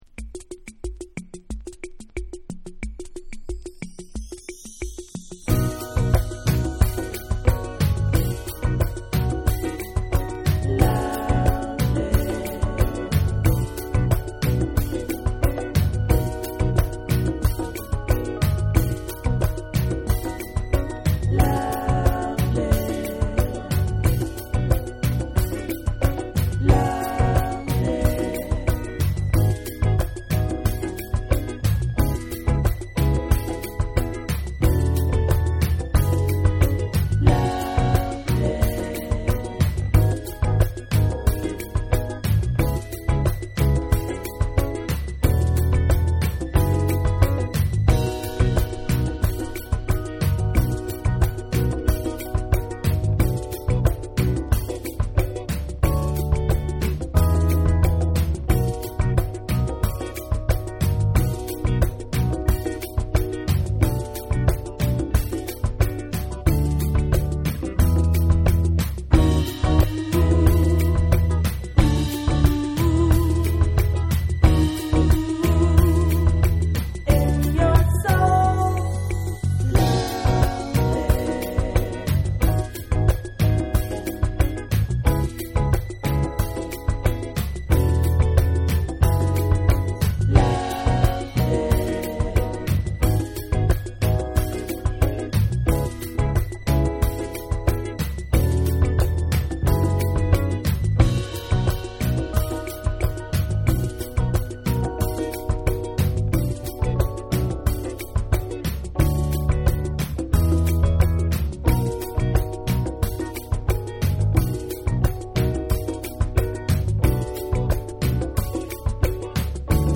SOUL & FUNK & JAZZ & etc / BREAKBEATS